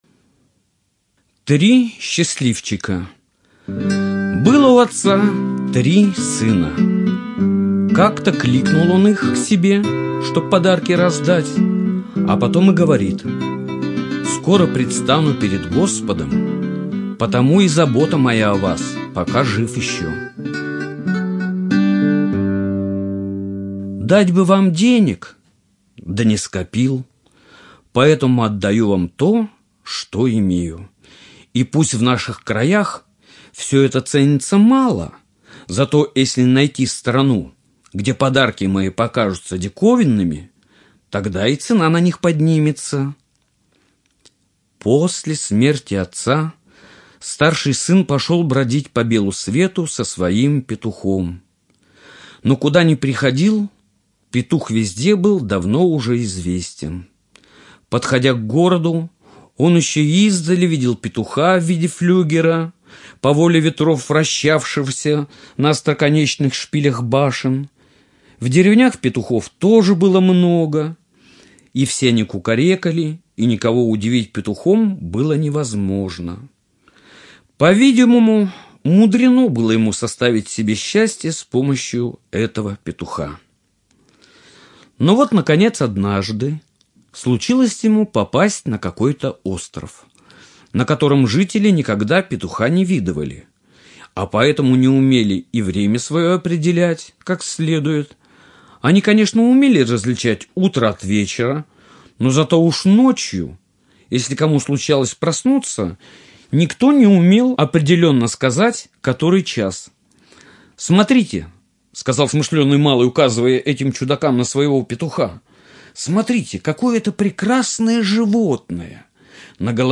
Три счастливчика - аудиосказка братьев Гримм. Сказка о трех братьях и необычном наследстве. Оставил отец своим сыновьям петуха, косу и кота.